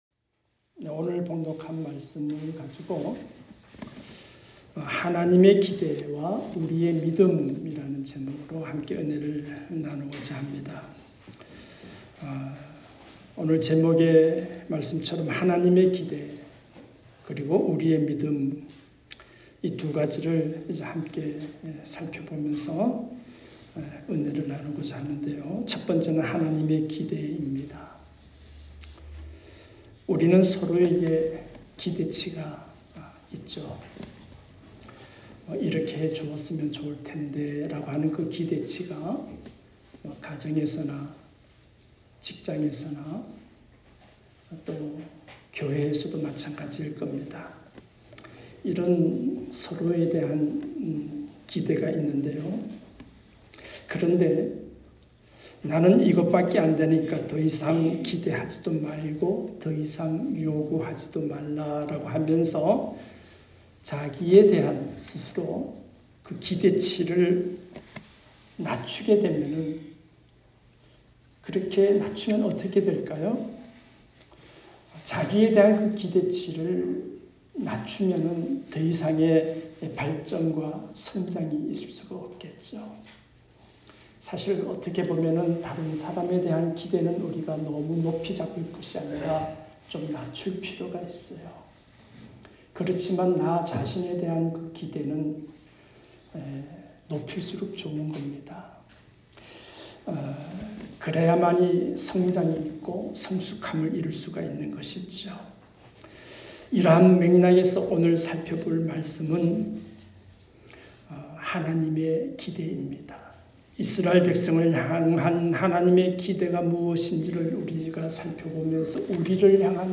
하나님의 기대와 우리의 믿음 ( 렘11:14-23 ) 말씀